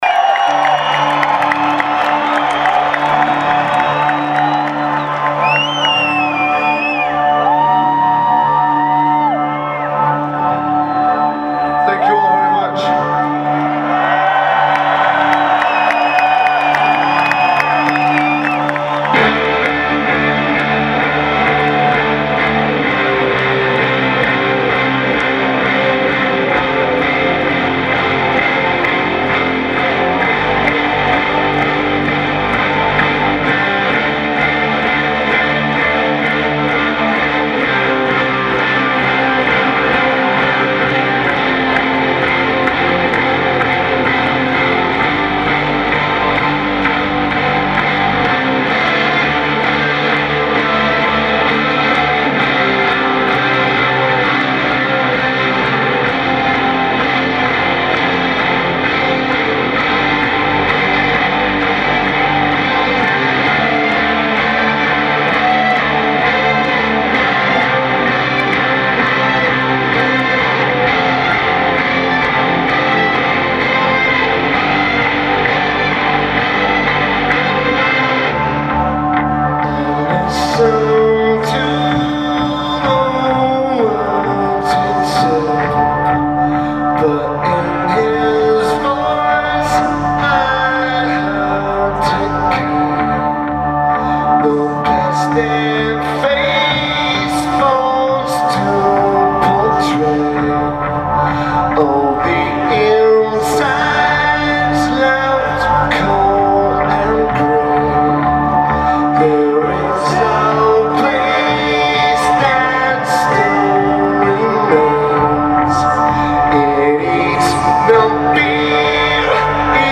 Phones 4u Arena
Lineage: Audio - AUD (Sony ICD-UX81)